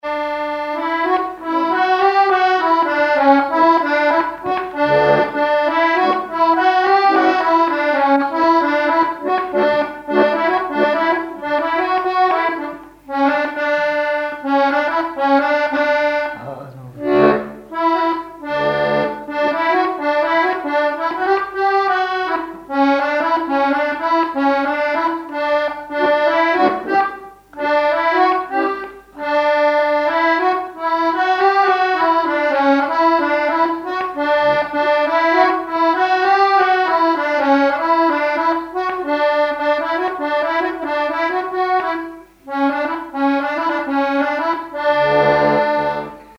Saint-Hilaire-de-Riez
Chants brefs - A danser
accordéon diatonique
Pièce musicale inédite